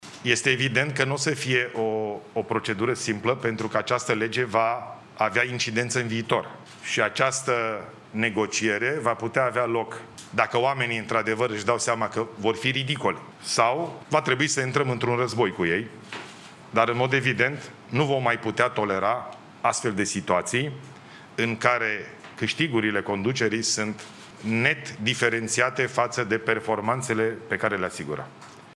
”Este evident că nu o să fie o procedură simplă pentru că această lege va avea incidență în viitor. Această negociere va putea avea loc dacă oamenii își dau seama că vor fi ridicoli sau va trebui să intrăm într-un război cu ei” a mai explicat premierul într-o conferință de presă la Palatul Victoria.